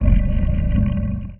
Sfx_creature_spikeytrap_idle_os_06.ogg